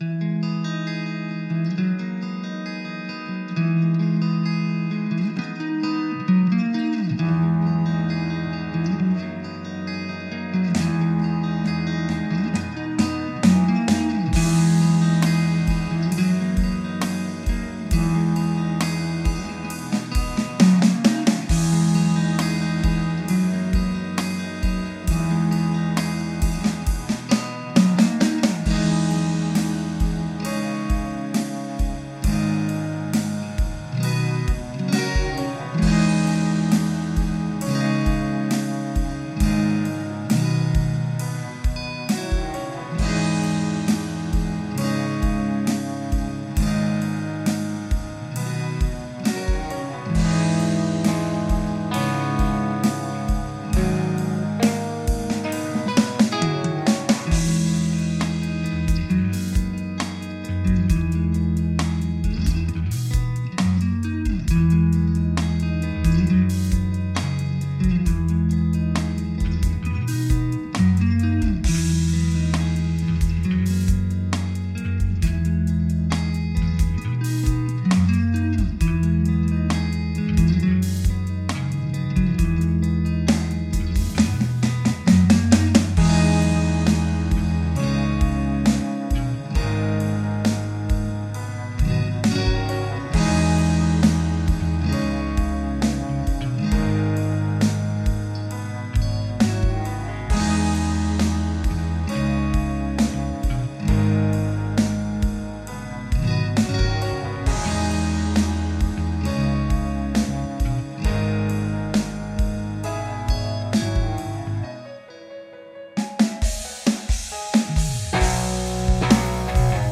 I'm working on an album and I'm having trouble getting a good balance and good warmer sound in this mix.
The bass is a Fender Jazz Bass recorded DI, then reamped, guitars were a Fender Mustang with Cab simulations turned off using third party Catharsis cab impulses, Piano is a Yamaha One (I think), with midi violins. Haven't recorded vocals yet, but already have written them. Would really like to warm up this mix.